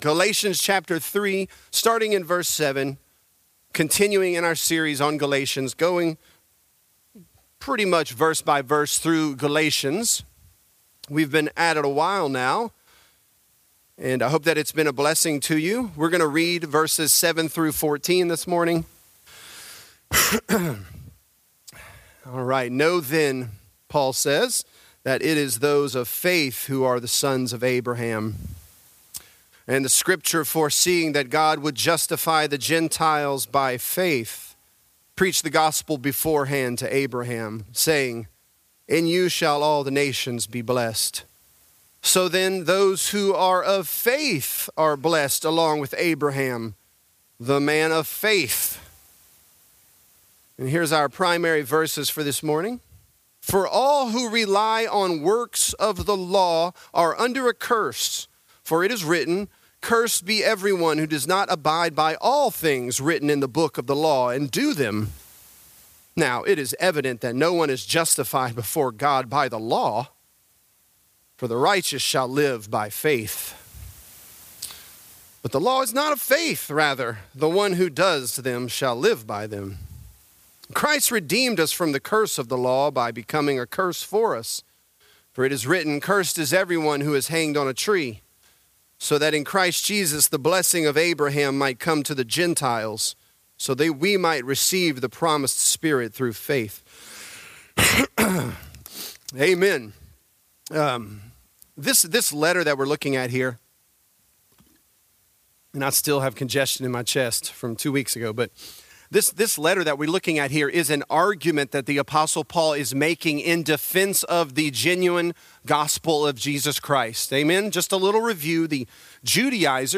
Galatians: The Blessed & The Cursed | Lafayette - Sermon (Galatians 3)